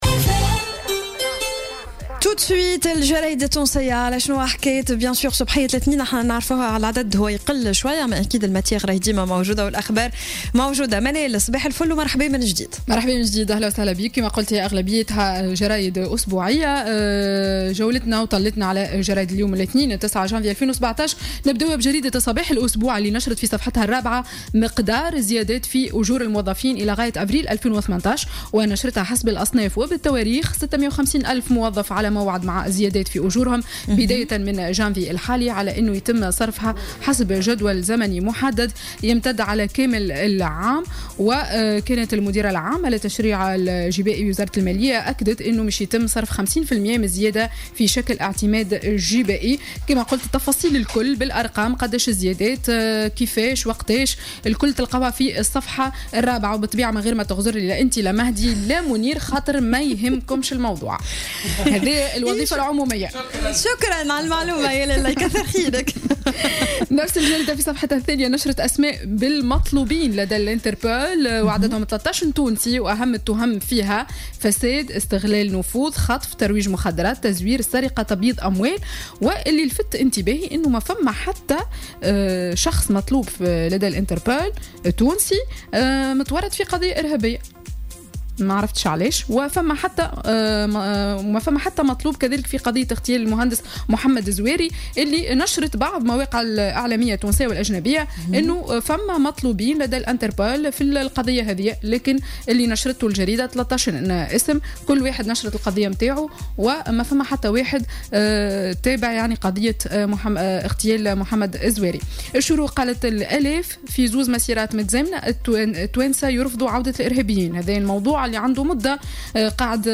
Revue de presse du lundi 9 janvier 2017